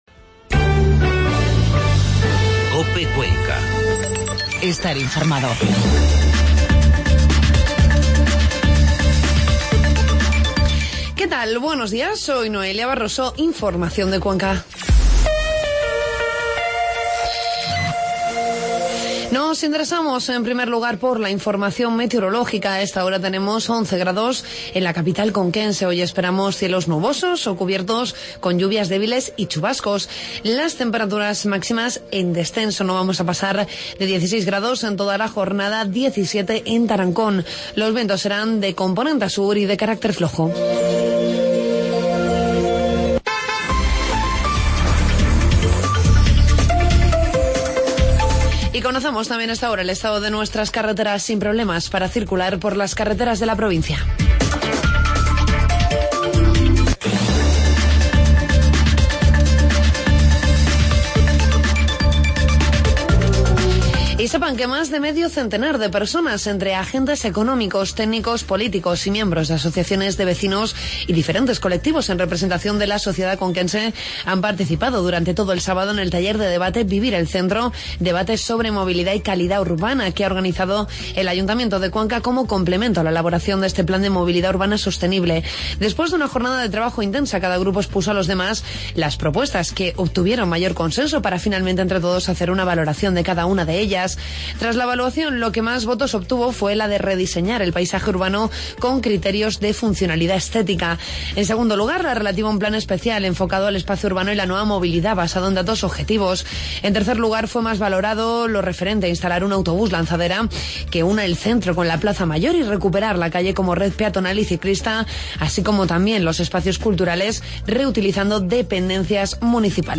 Informativo matinal COPE Cuenca